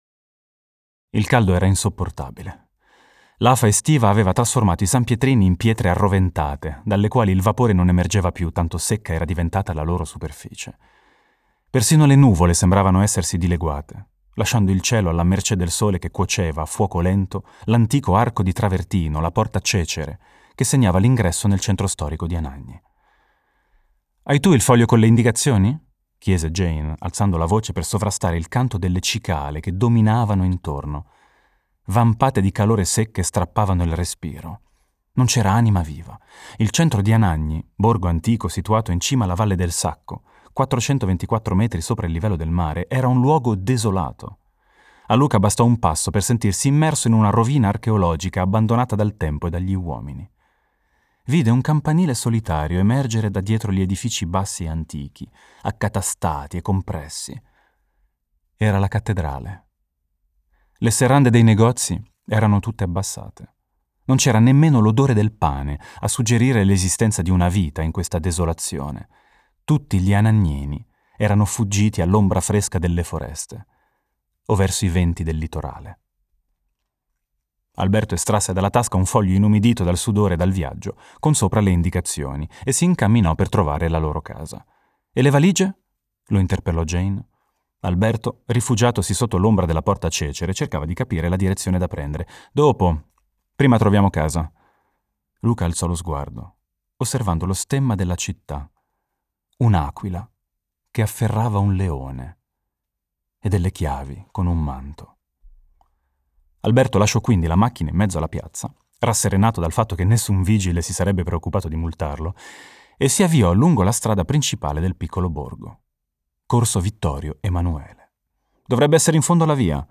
Ascolta e leggi il secondo capitolo in audiolibro dell'Anello Di Saturno